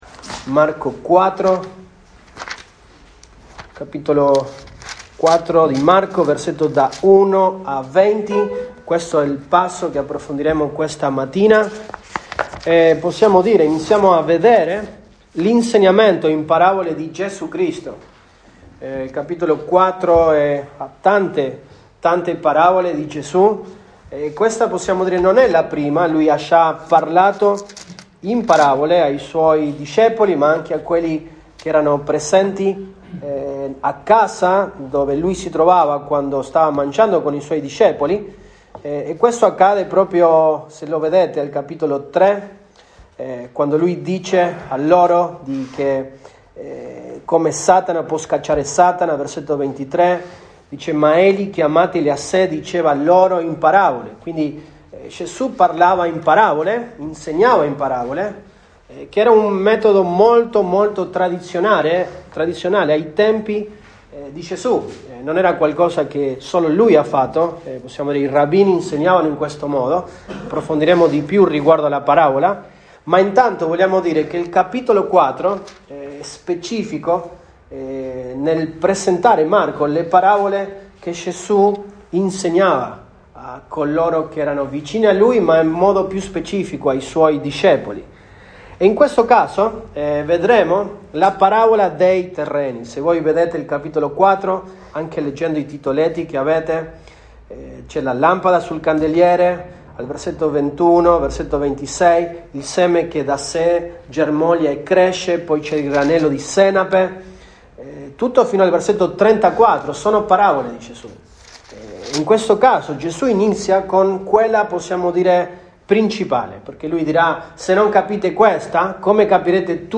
Mar 19, 2023 L’insegnamento in parabole di Gesù Cristo, 1 parte MP3 Note Sermoni in questa serie L’insegnamento in parabole di Gesù Cristo, 1 parte.